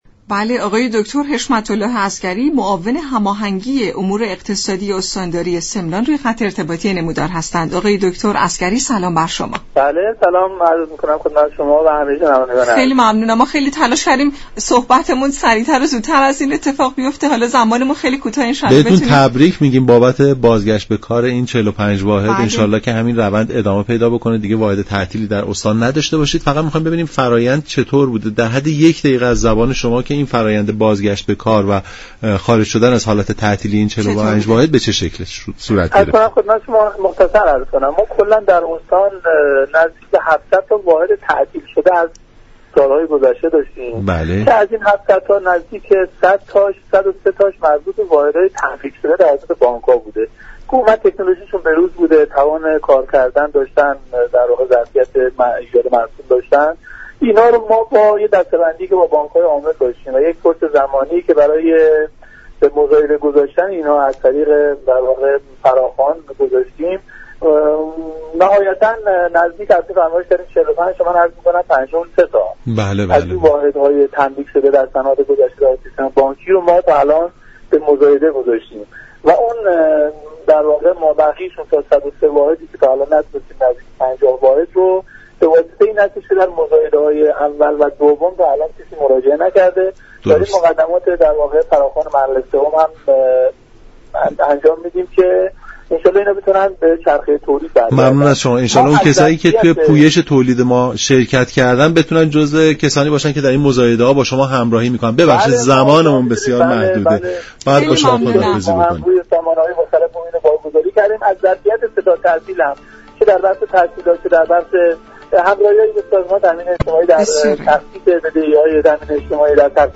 به گزارش شبكه رادیویی ایران، دكتر حشمت اله عسگری معاون هماهنگی امور اقتصادی استانداری سمنان در گفت و گو با برنامه «نمودار» از بازگرداندن 53 كارخانه تعطیل و تملیك‌شده این استان به چرخه تولید در یك سال اخیر خبر داد و گفت: از میان 700 واحد تعطیل شده در استان سمنان 103 واحد تملیكی وجود دارد كه از این میزان توانسته ایم 53 واحد را از طریق مزایده از تعطیلی خارج و به چرخه تولید بازگردانیم.